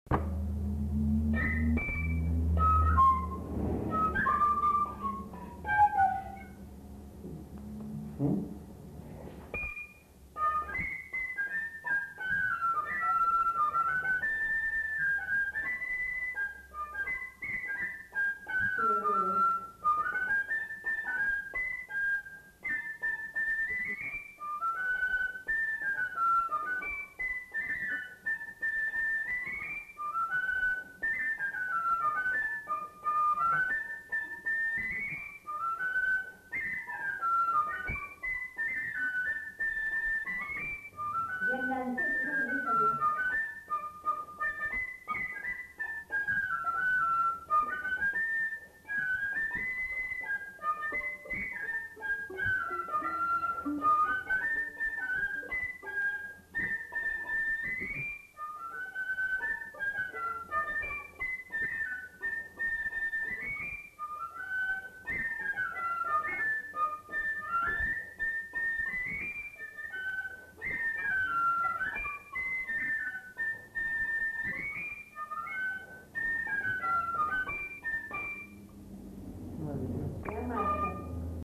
Scottish
Lieu : Bazas
Genre : morceau instrumental
Instrument de musique : fifre
Danse : scottish